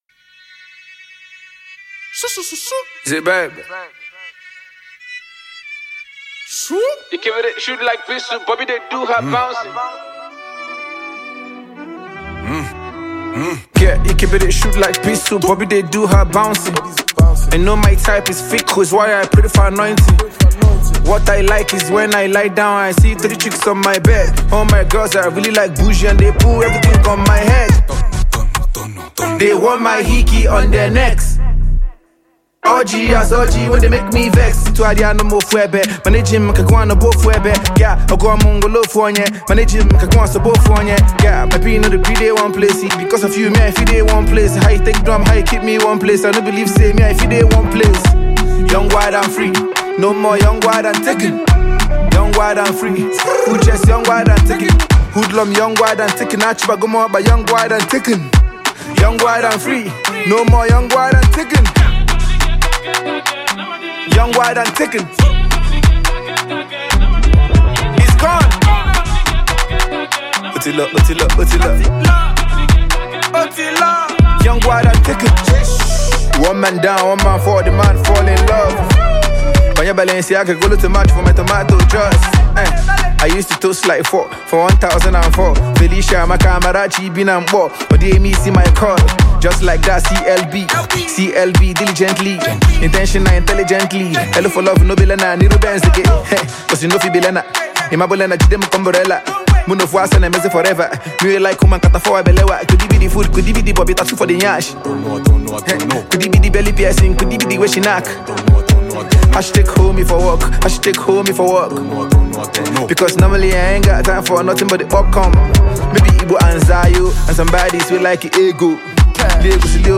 Multi-talented Nigerian music sensation and rapper